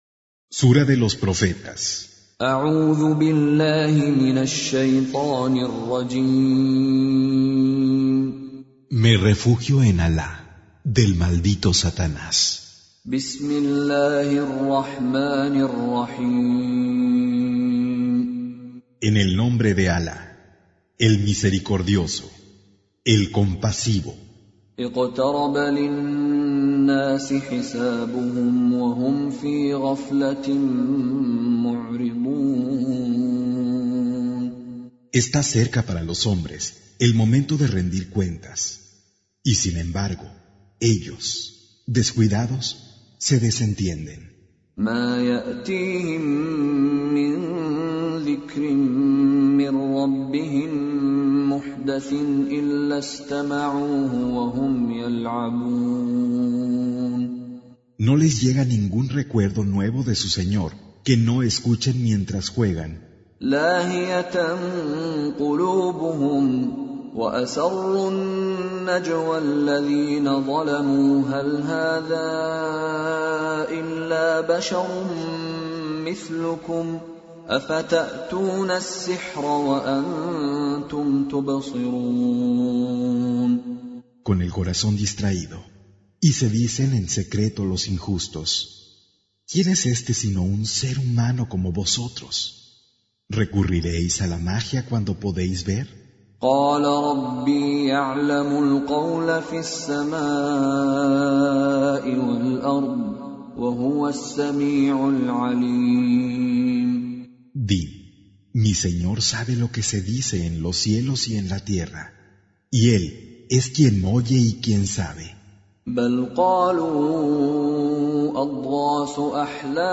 Traducción al español del Sagrado Corán - Con Reciter Mishary Alafasi
Surah Sequence تتابع السورة Download Surah حمّل السورة Reciting Mutarjamah Translation Audio for 21.